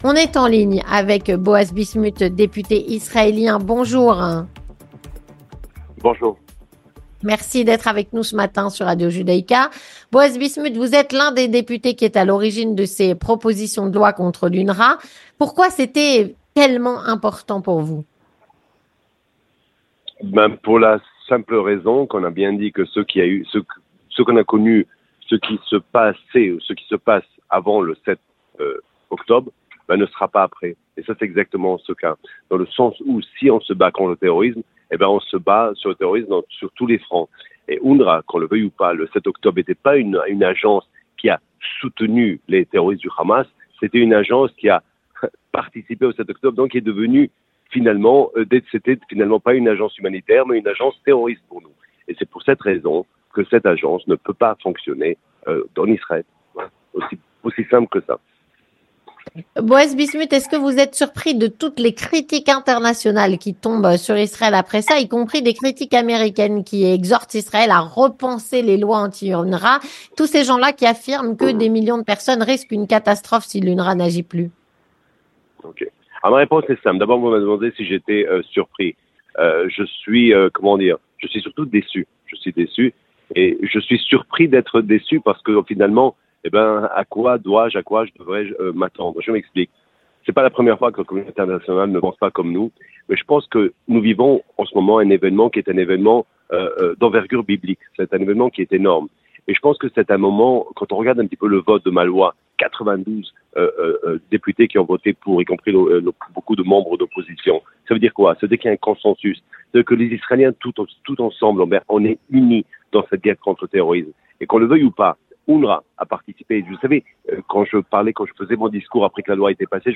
Avec Boaz Bismuth, l'un des grands artisans, et donc partisan, de cette loi.